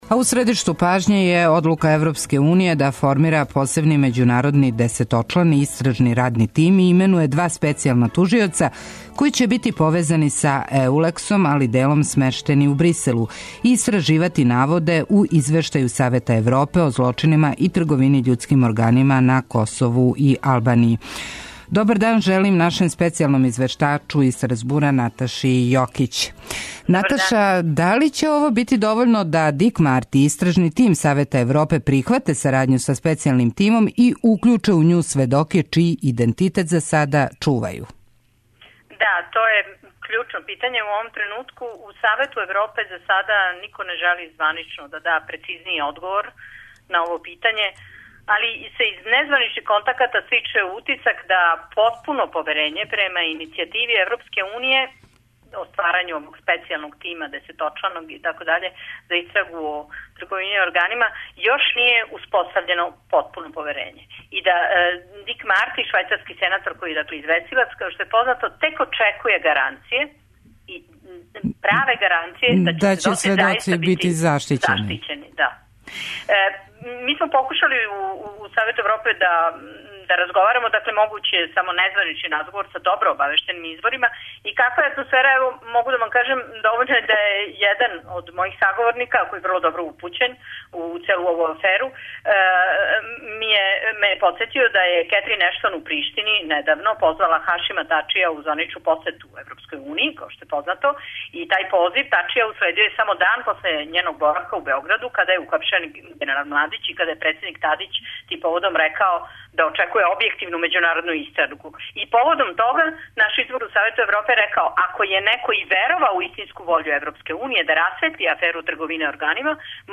Гост емисије је Саша Пауновић, председник општине Параћин и председник Сталне конференције градова и општина.